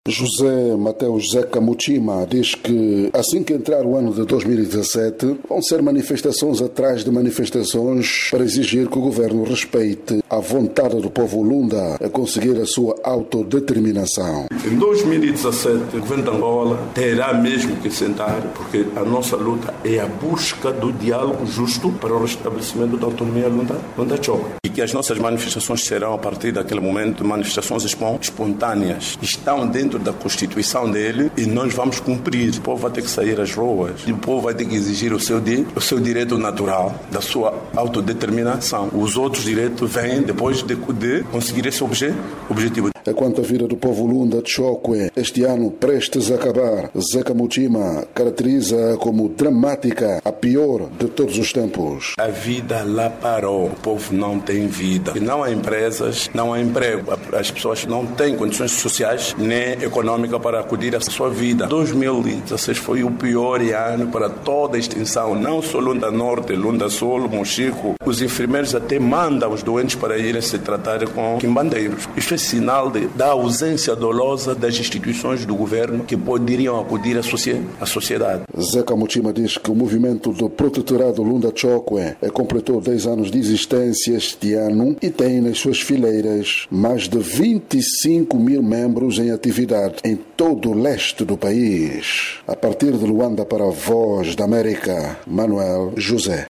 Situação nas Lundas em discussão com acivista pró autonomia - 1:36